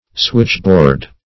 switchboard \switch"board`\ n.